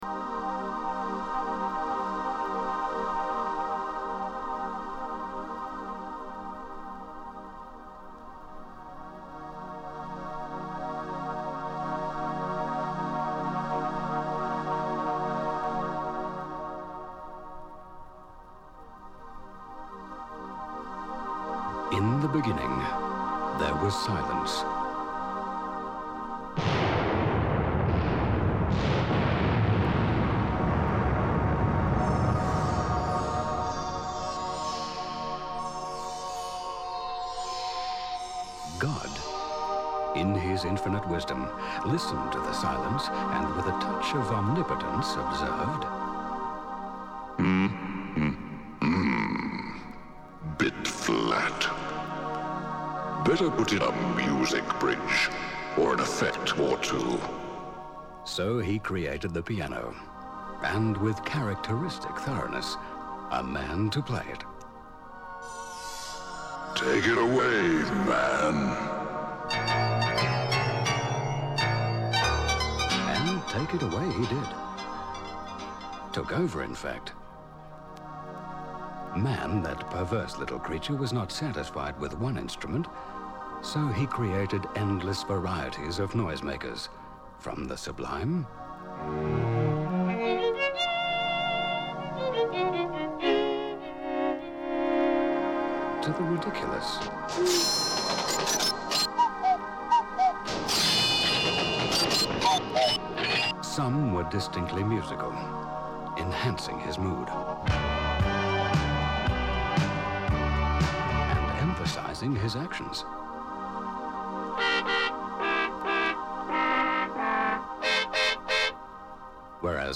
Demo Emulator Face A